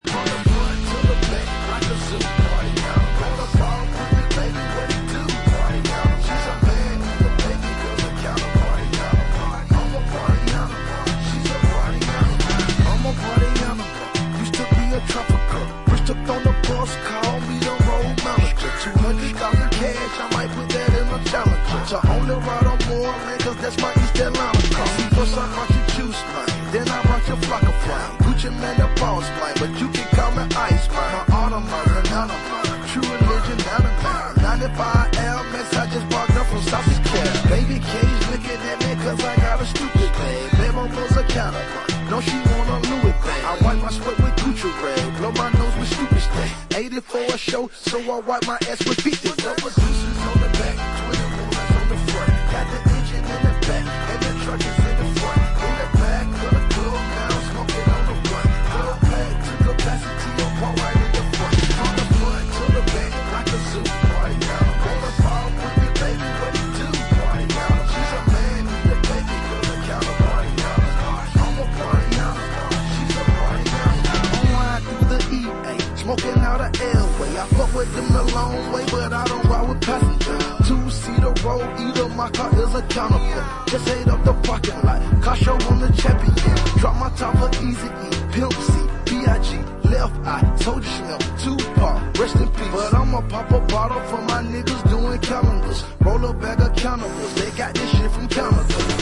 bootlegs
Hip Hop